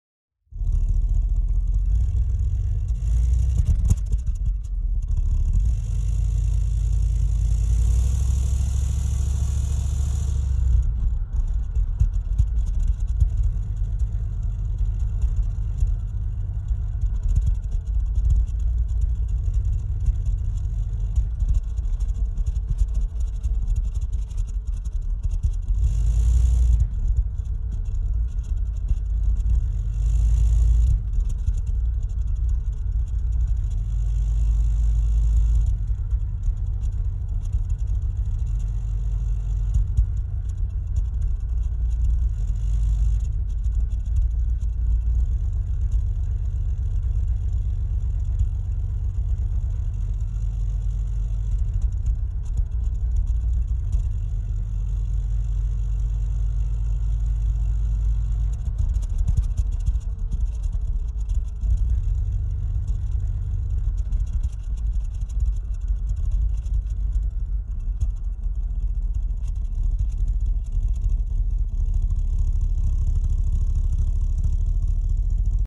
Звуки выхлопной системы